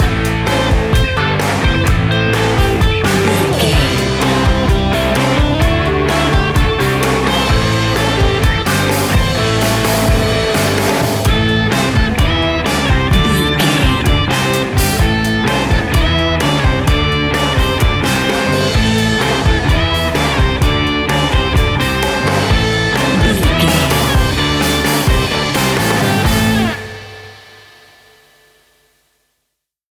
Ionian/Major
hard rock
heavy metal
blues rock
distortion
punk metal
instrumentals